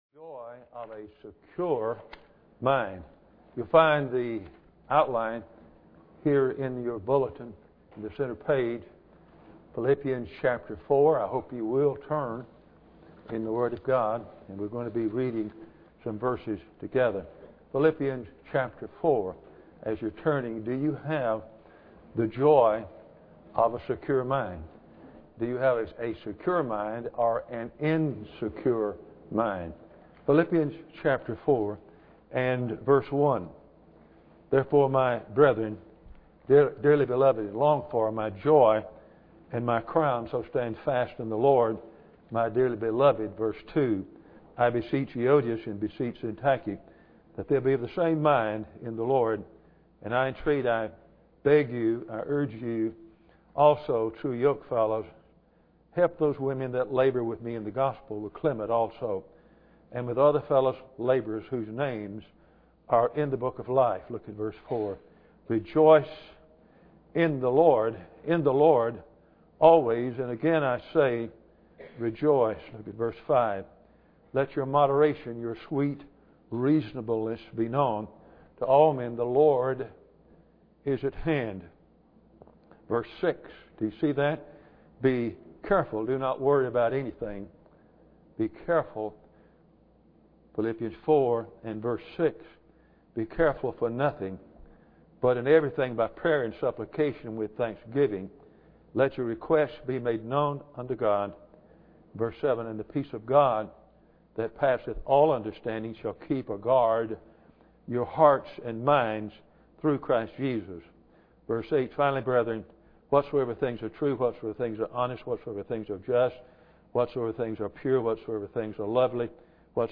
The points considered in this sermon are :
Service Type: Sunday Morning